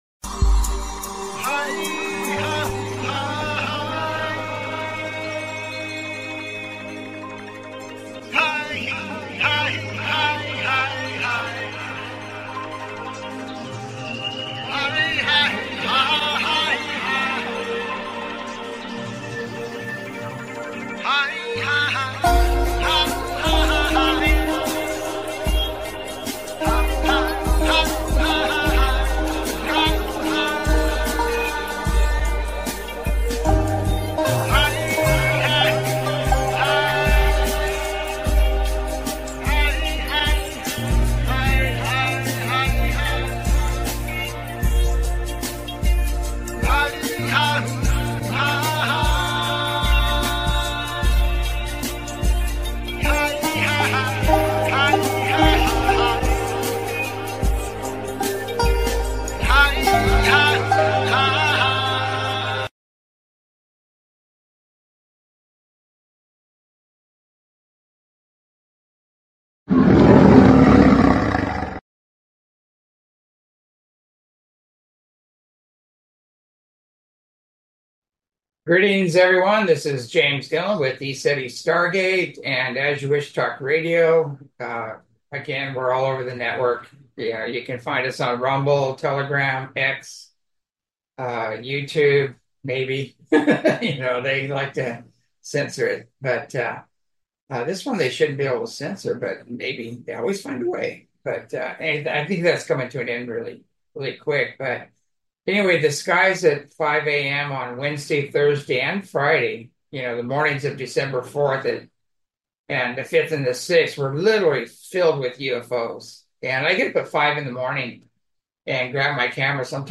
Talk Show Episode, Audio Podcast, As You Wish Talk Radio and Armadas Of UFOs, Skyquakes, They Are Here And Its Good on , show guests , about Armadas Of UFOs,Skyquakes,They Are Here And Its Good, categorized as Earth & Space,News,Paranormal,UFOs,Philosophy,Politics & Government,Science,Spiritual,Theory & Conspiracy
As you Wish Talk Radio, cutting edge authors, healers & scientists broadcasted Live from the ECETI ranch, an internationally known UFO & Paranormal hot spot.